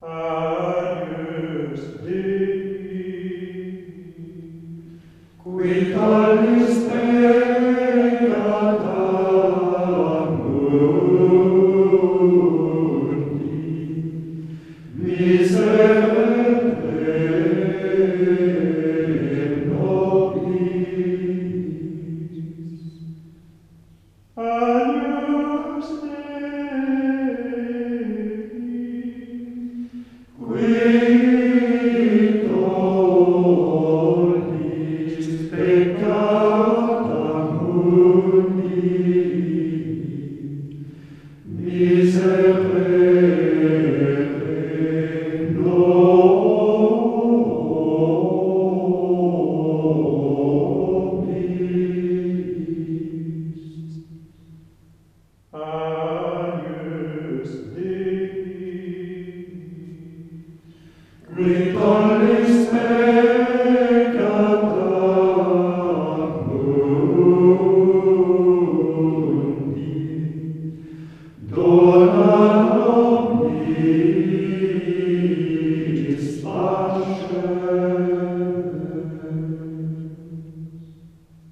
• grégorien croix introït offertoire agnus dei communion alléluia graduel
Il emprunte sa mélodie au 1er mode. Les première et troisième invocations sont identiques, et la seconde tranche sur celles qui l’encadrent, par son élévation mélodique initiale.
L’intonation est douce et sobre, peu élevée mélodiquement puisqu’elle ne fait appel qu’aux cordes fondamentales du mode de Ré : le Ré, bien sûr, le Mi et le Fa pour la tierce caractéristique du mode, et le Do grave qui joue le rôle de sous-tonique.
On a donc un Agnus Dei bien construit, bien régulier, très paisible, typique du 1er mode avec la paix profonde qui le caractérise.